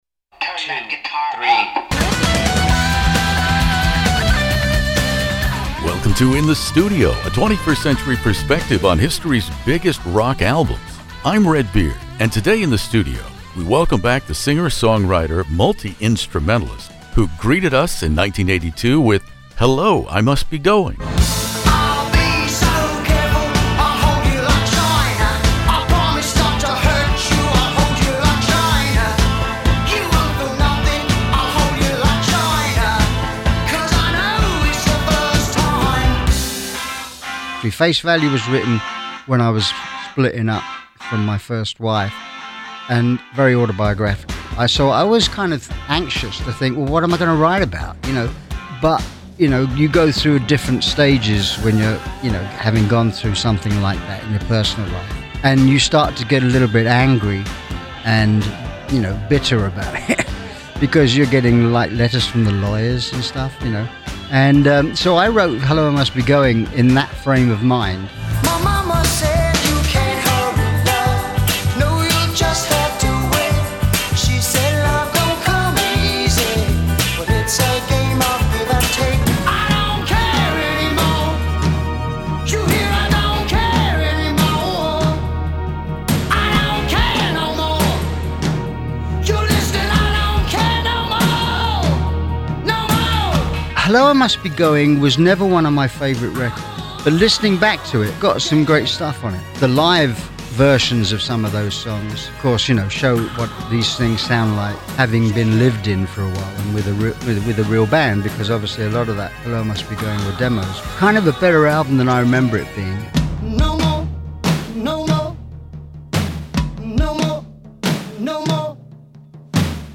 No one on the planet knows this better now than my guest Phil Collins.